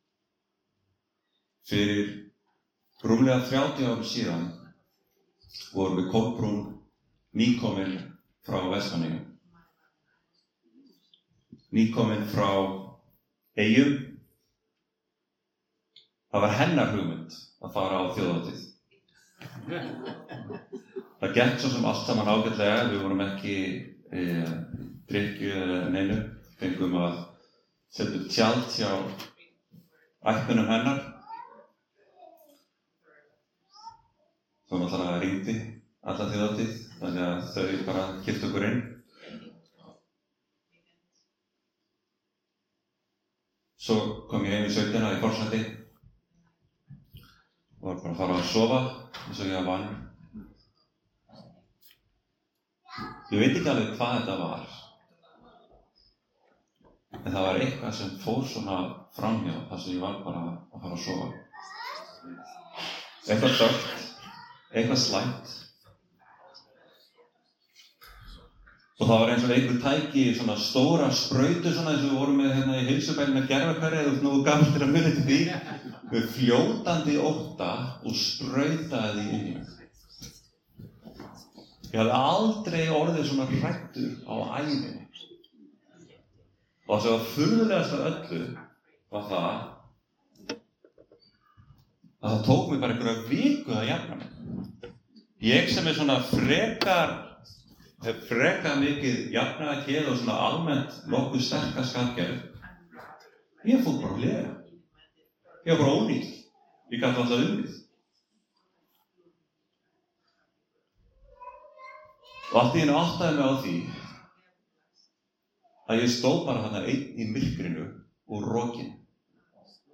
Predikað á Páskum í Hvítasunnukirkjunni í Vestmannaeyjum.